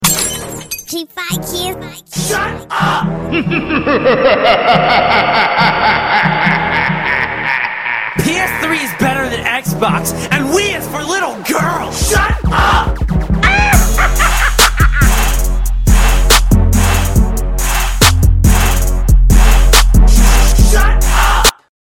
ps3-funny.mp3